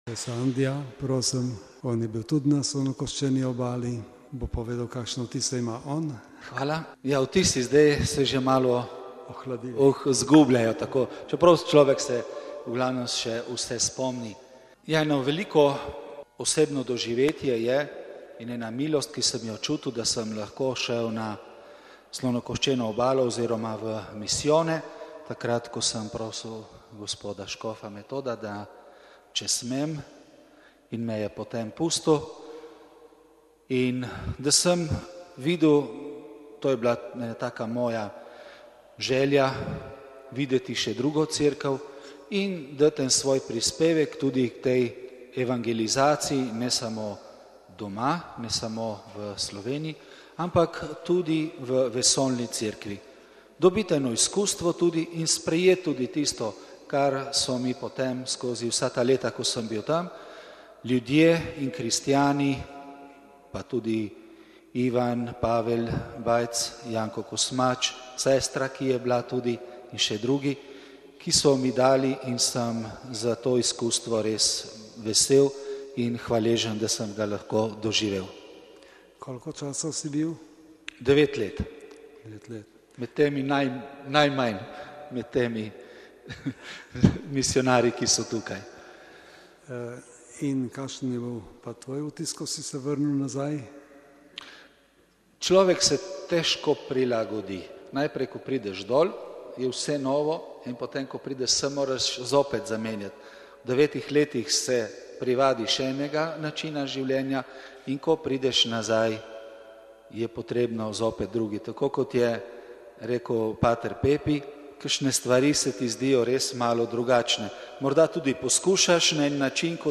Po sveti maši pa je bilo pogovorno srečanje s sedanjimi ali nekdanjimi slovenskimi misijonarji in misijonarkami.
Audio pogovora